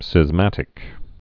(skĭz-mătĭk, sĭz-)